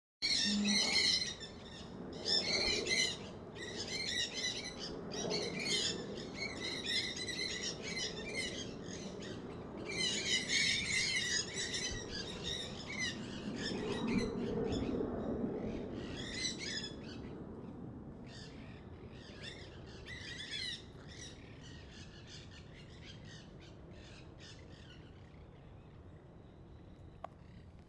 Nanday Parakeet (Aratinga nenday)
Life Stage: Adult
Location or protected area: Reserva Ecológica Costanera Sur (RECS)
Condition: Wild
Certainty: Observed, Recorded vocal